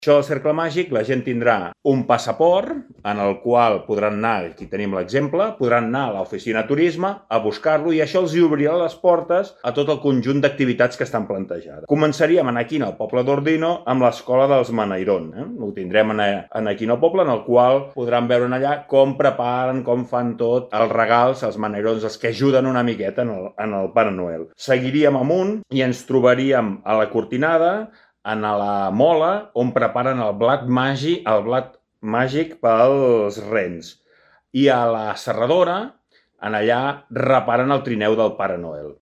Segons el conseller de Turisme, Dinamització i Esports, Jordi Serracanta, el “Cercle Màgic” serà “un viatge ple de fantasia pensat per a un turisme familiar.